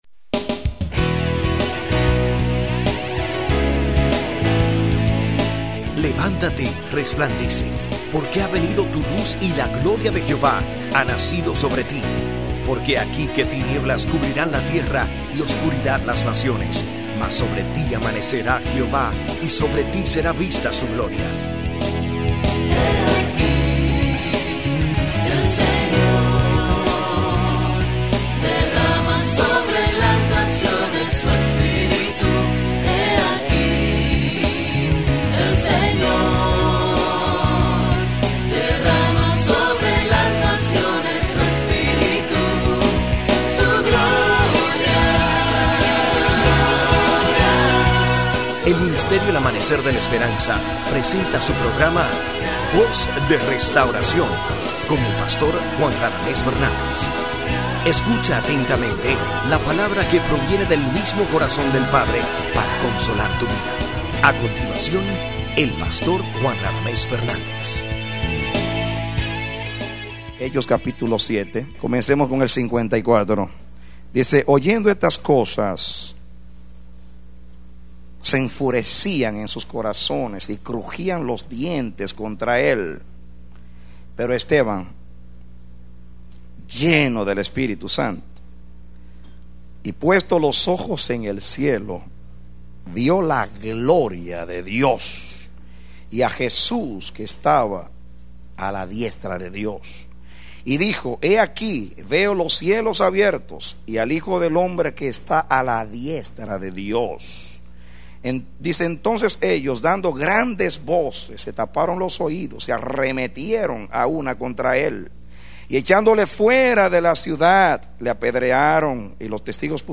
A mensaje from the serie "Mensajes." Predicado Mayo 4, 2014